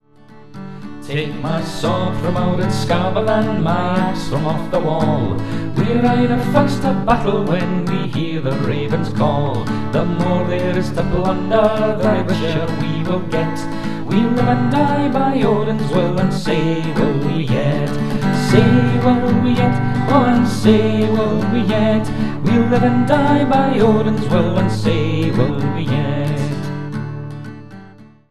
Ibanez PF60CENT1202 'semi' acoustic.
Bodrhan.
Quickshot mic (yes, really!)